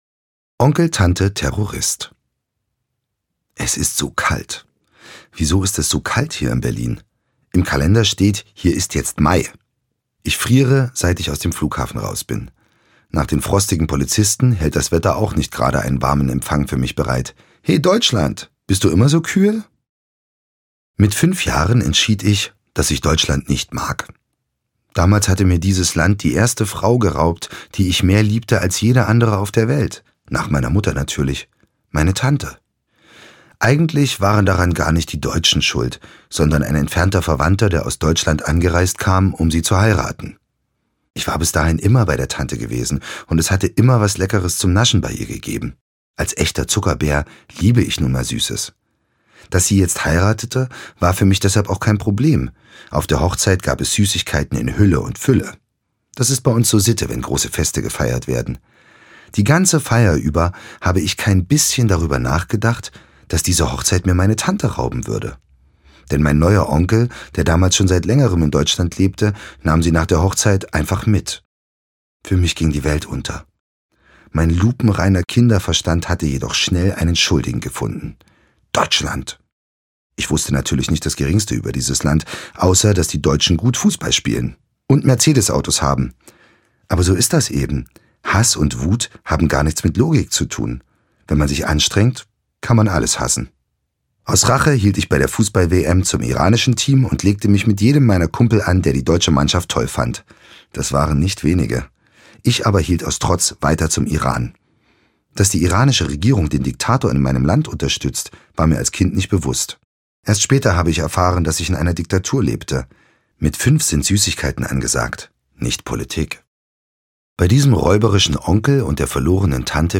Ich komm auf Deutschland zu - Firas Alshater - Hörbuch